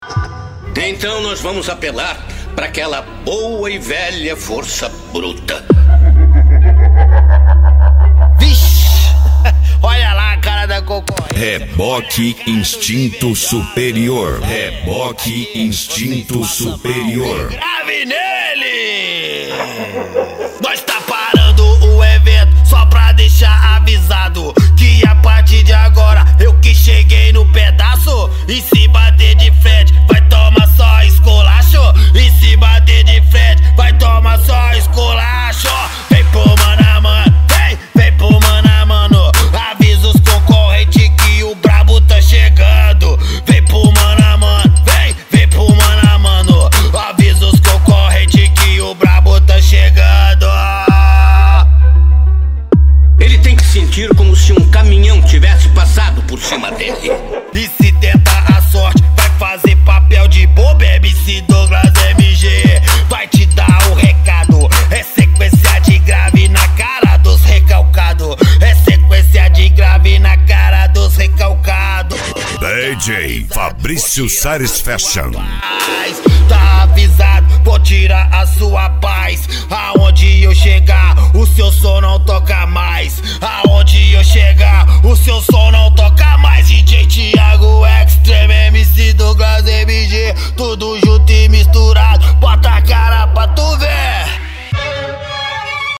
Bass
Mega Funk